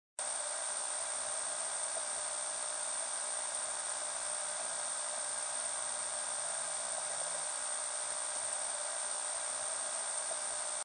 Звуки телевизора
На этой странице собраны разнообразные звуки телевизора: от характерного писка при включении до статичных помех и переключения каналов.